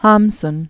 (hämsən, -sn), Knut Pen name of Knut Pedersen. 1859-1952.